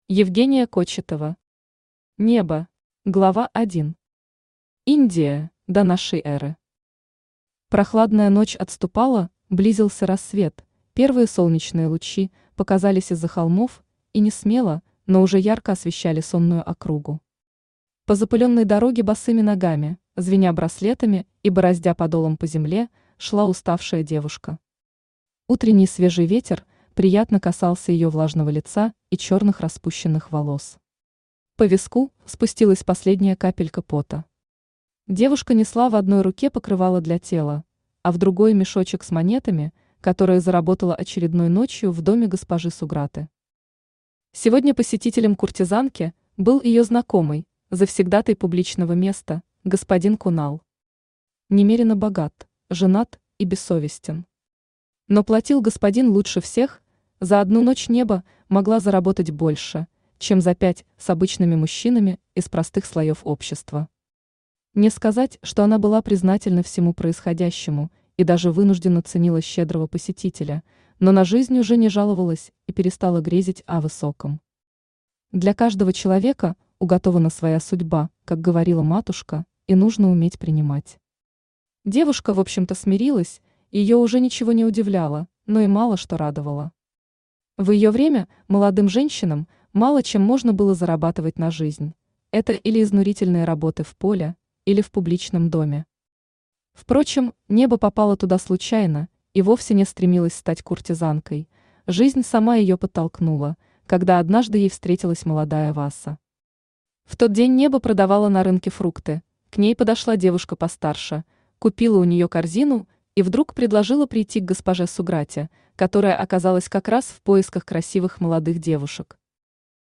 Aудиокнига Неба Автор Евгения Олеговна Кочетова Читает аудиокнигу Авточтец ЛитРес.